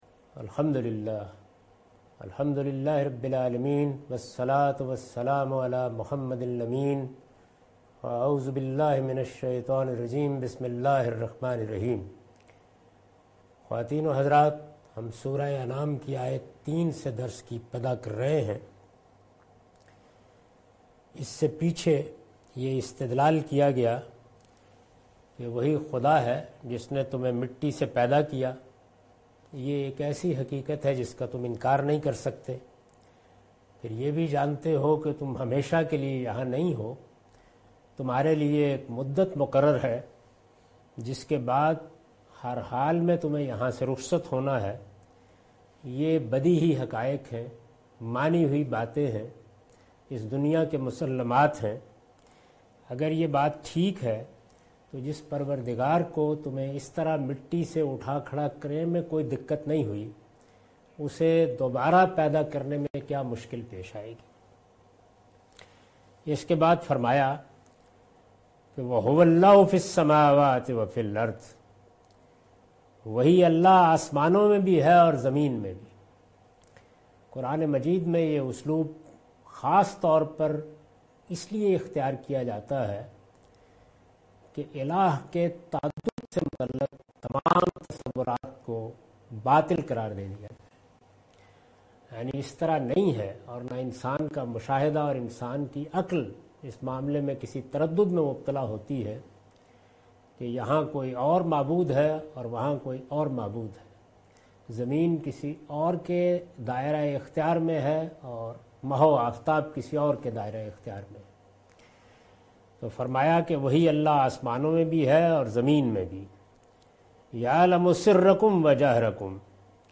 Surah Al-Anam - A lecture of Tafseer-ul-Quran – Al-Bayan by Javed Ahmad Ghamidi. Commentary and explanation of verse 3,4,5,6,7,8,9,10,11 and 12.